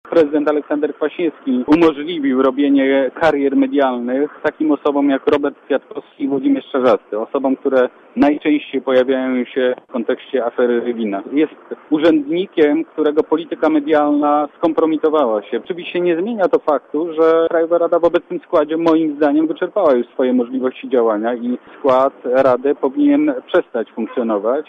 Mówi Jarosław Sellin (192 KB)